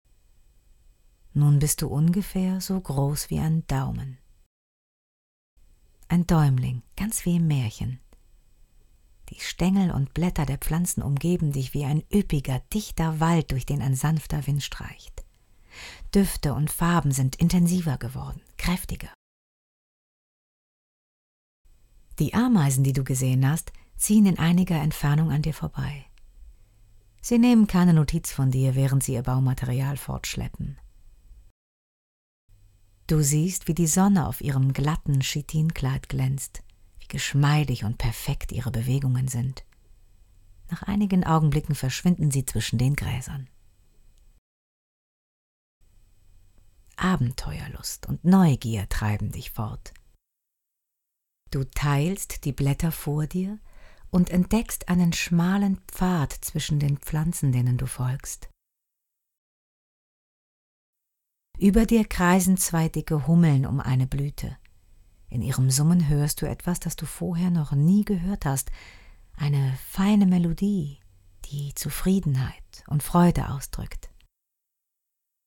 Geführte Meditation und Entspannungstechnik mit Hypnose und Suggestionen
Die Phantasiereise für Erwachsene wurde von einer professionellen Hörbuch-Sprecherin im Studio eingesprochen und garantiert besten Hörgenuß!